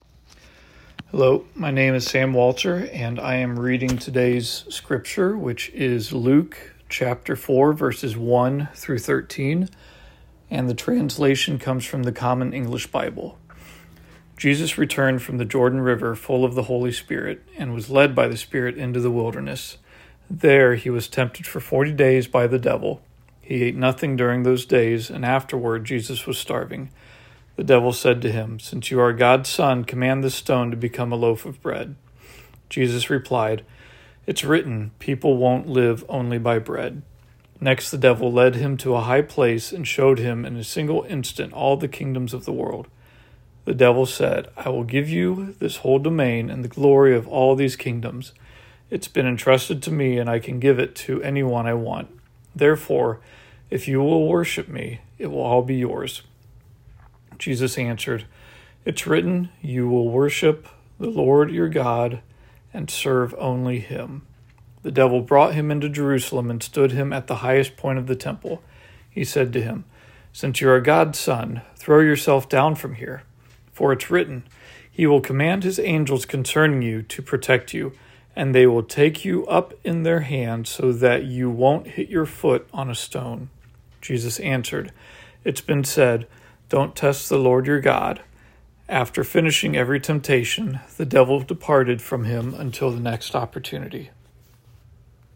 March-23rd-Scripture-Reading.wav